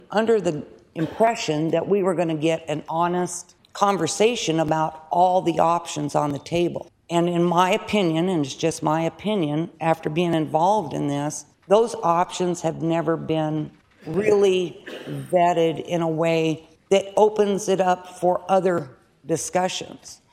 Republican Rep. Liz May from Kyle said the process hasn’t been transparent.